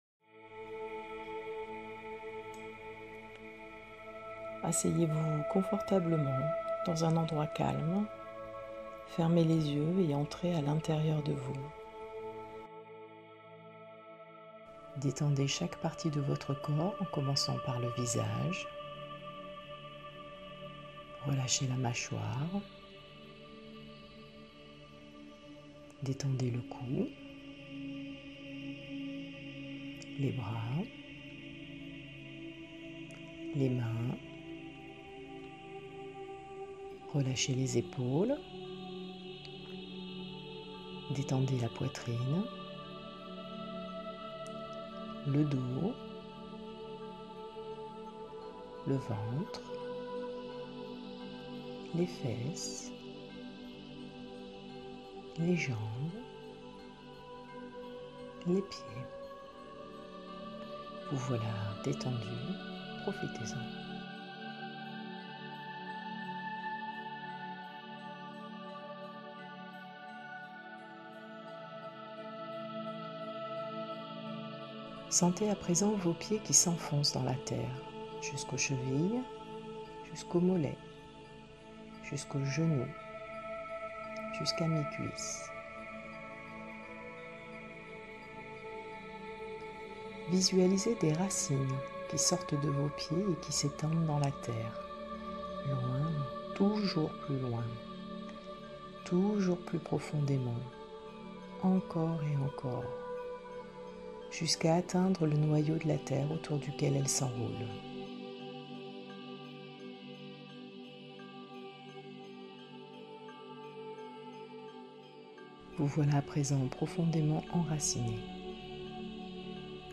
2021 CHANTS VIBRATOIRES audio closed https
Meditation-aux-13-rayons-sacres.mp3